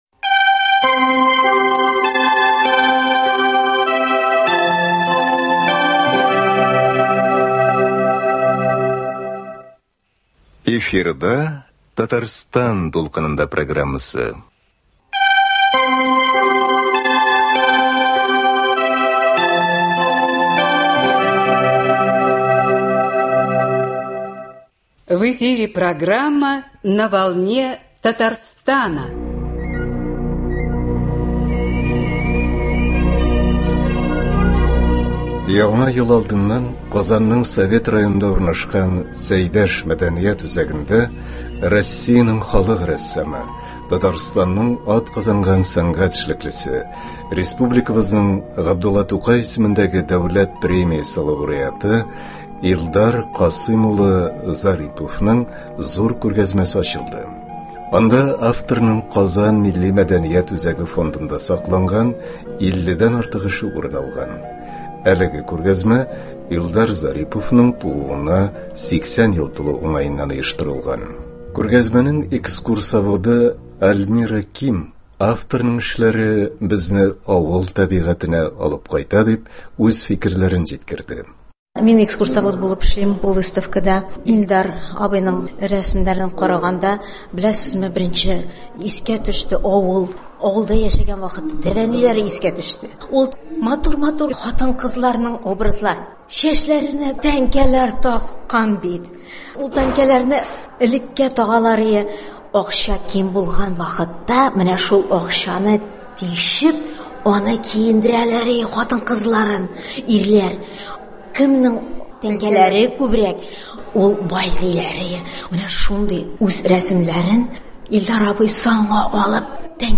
Бүгенге тапшыруда Россиянең халык рәссамы,Татарстанның атказанган сәнгать эшлеклесе, республикабызның Габдулла Тукай исемендәге Дәүләт премиясе лауреаты